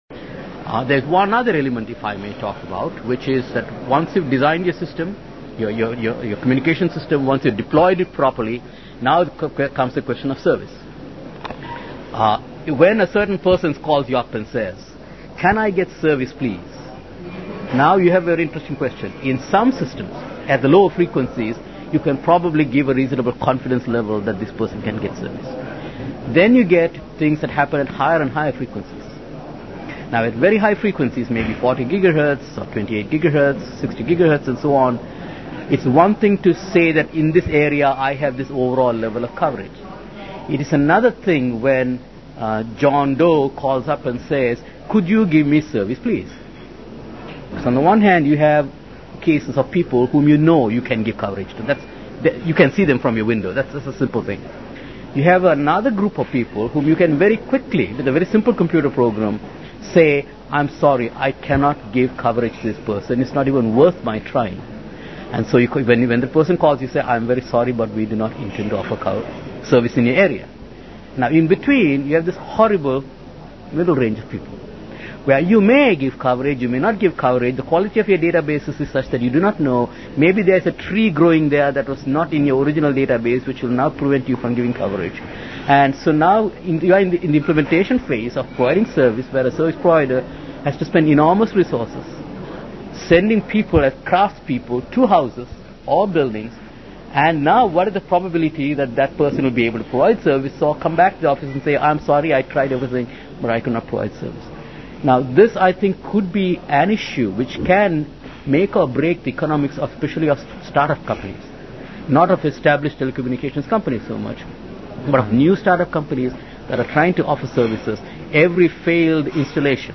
Audio Interviews